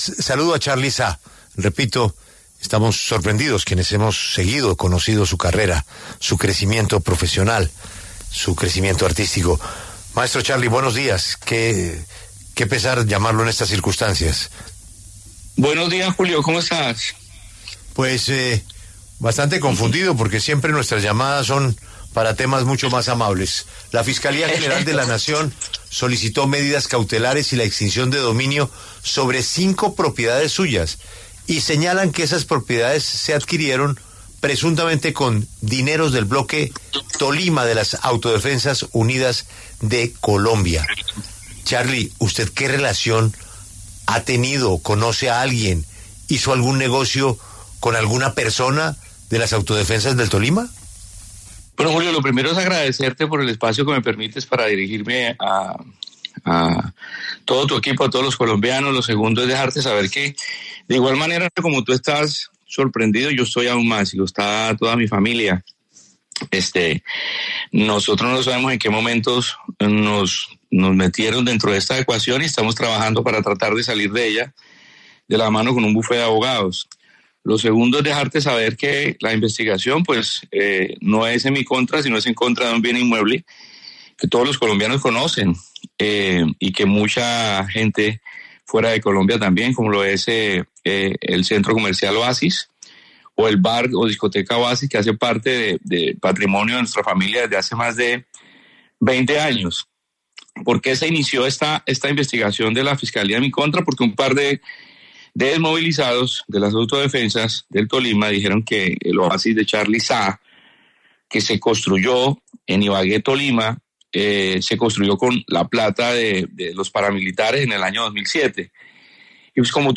El artista Charlie Zaa conversó en La W sobre la investigación que la Fiscalía lleva en su contra por una presunta relación suya con las Autodefensas.